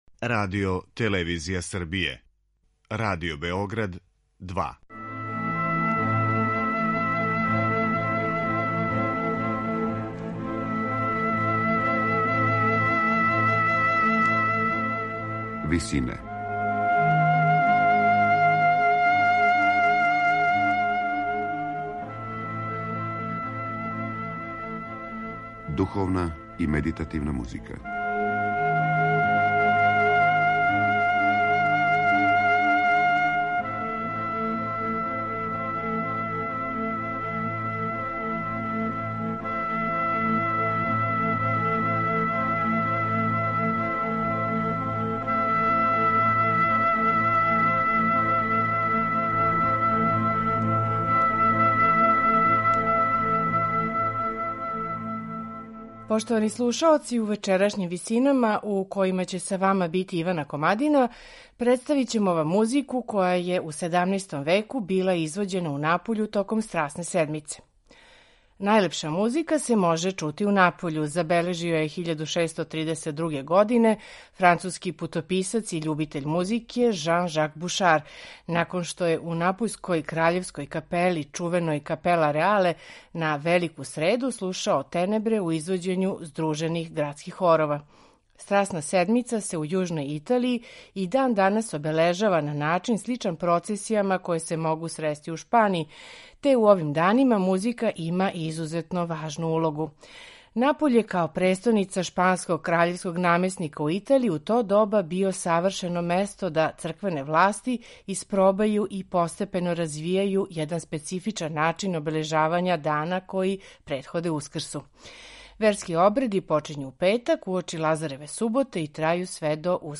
сопран и ансамбл „I turchini"